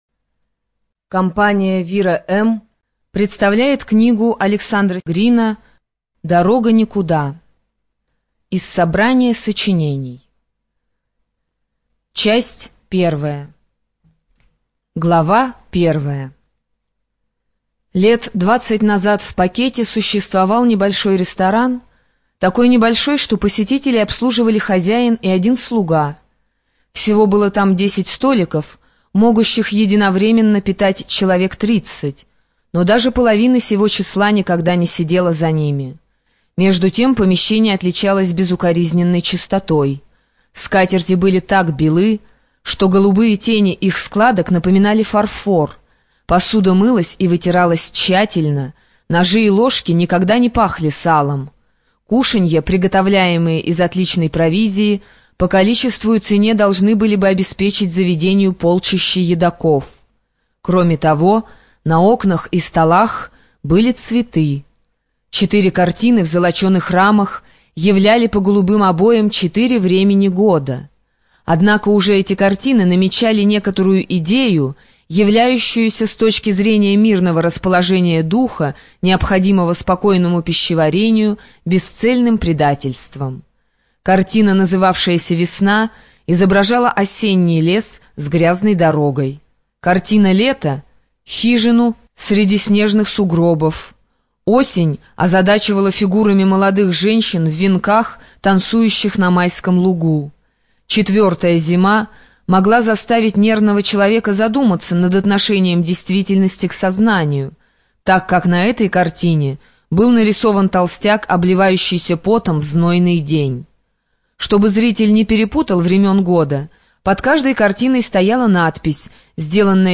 ЖанрКлассическая проза
Студия звукозаписиВира-М